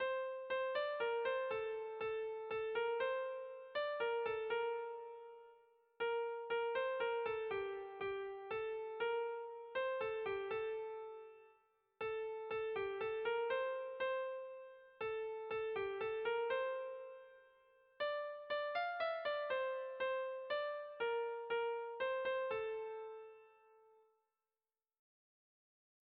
Sentimenduzkoa
Zortziko txikia (hg) / Lau puntuko txikia (ip)
A-B-C-D